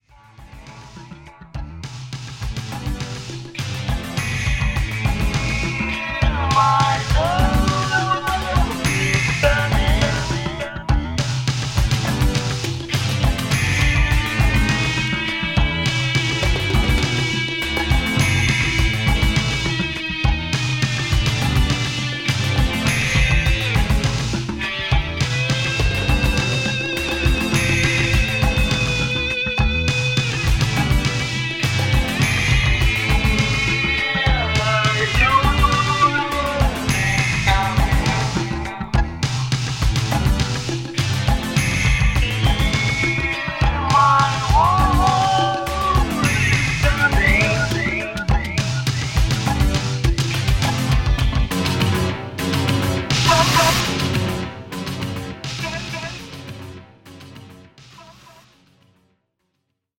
’60’~’70年代のポップ/ソウル・ヒットを'80年代のサウンド・センスでカバーした好盤です。